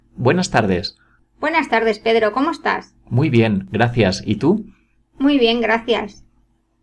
Recording: 0004 Level: Beginners Spanish Variety: Spanish from Spain
Transcribe the whole conversation by writing word by word what the speakers say.